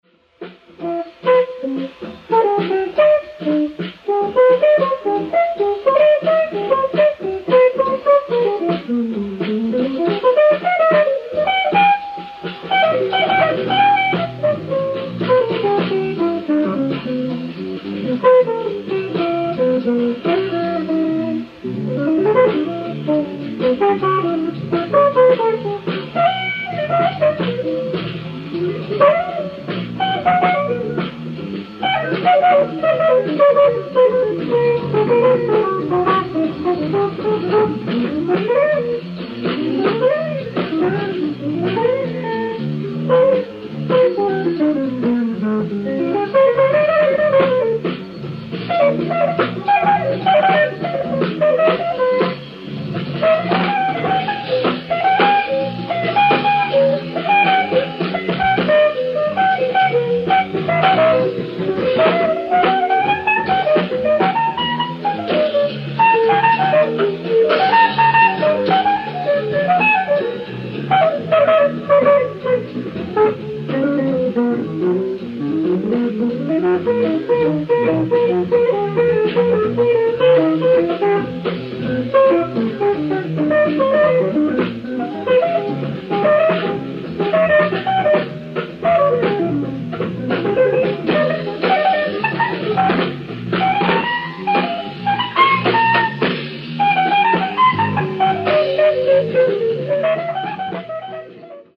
ディスク１：ライブ・アット・ジャズ・ワークショップ、ボストン 06/16/1976
※試聴用に実際より音質を落としています。
Disc 1(Noizeless)